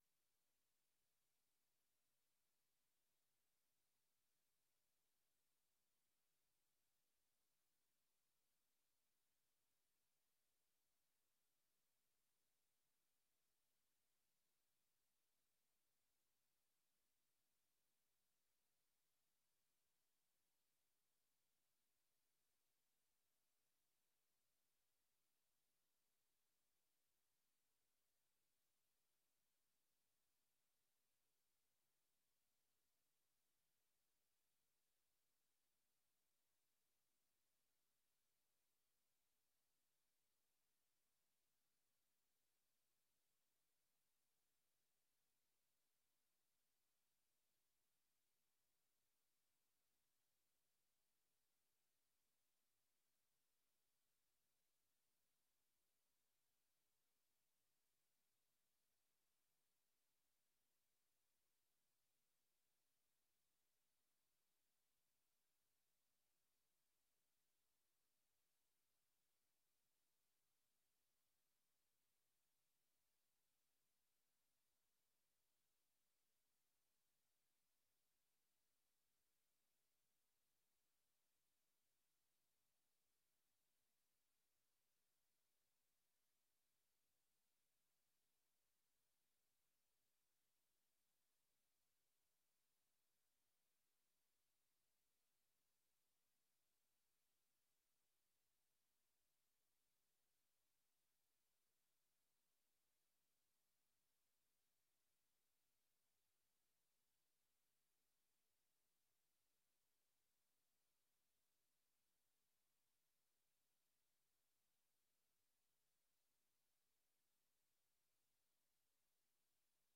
Oordeelsvormende vergadering(en) 14 oktober 2025 20:00:00, Gemeente West Betuwe
Voorrondezaal Lingewaal